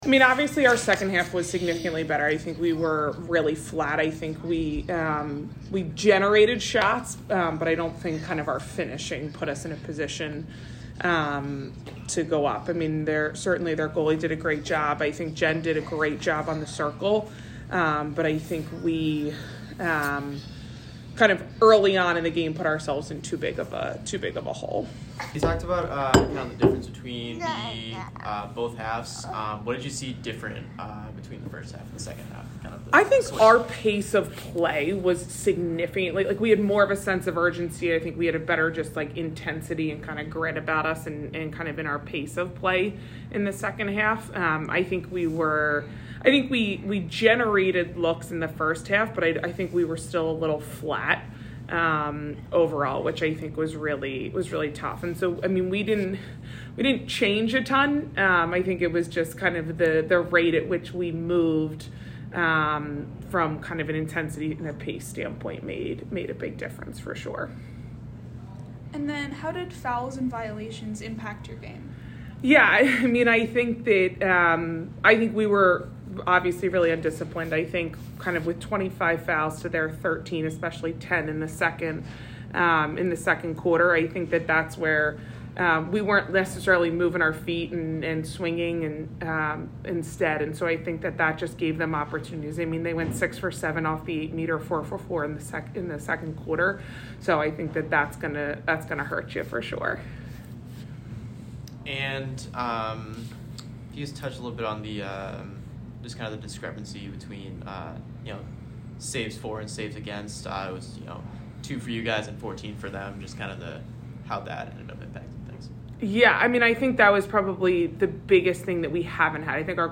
Holy Cross Postgame Interview